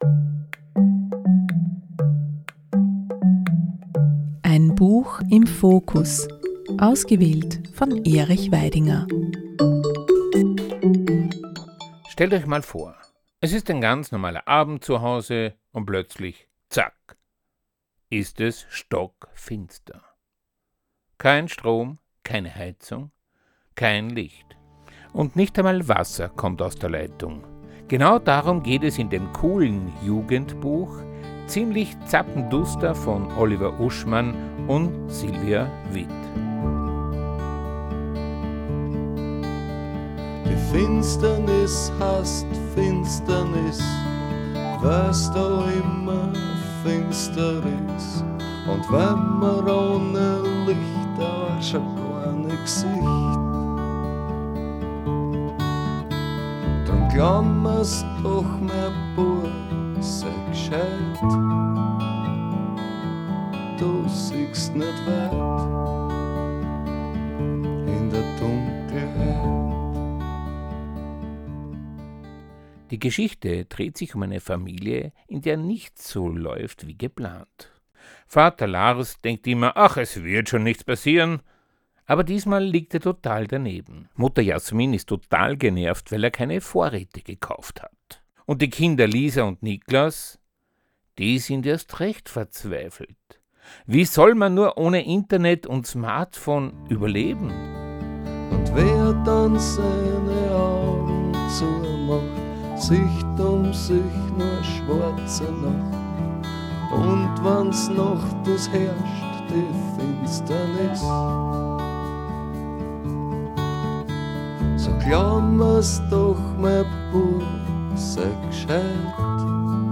Der Song „Die Finsternis“ zu dieser Sendung stammt von Wolfgang Ambros, was ich sehr passend fand.